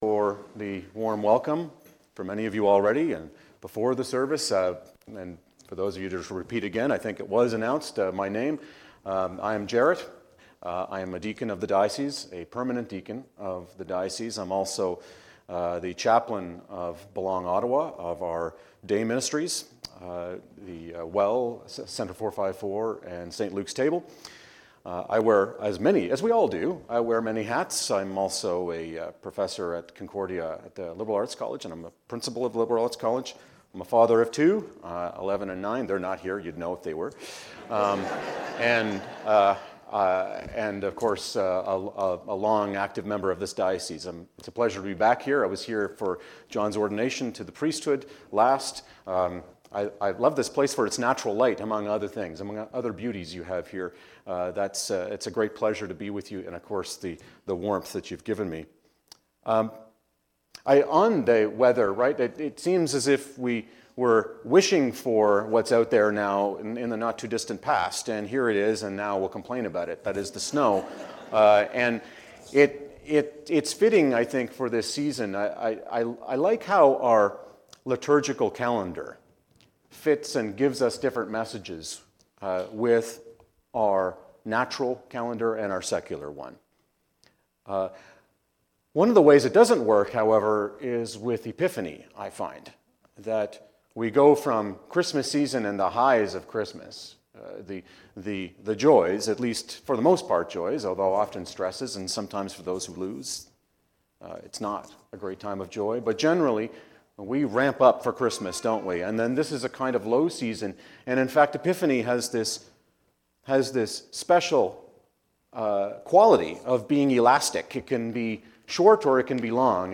Encountering the Incarnate Word. A sermon for the season of Epiphany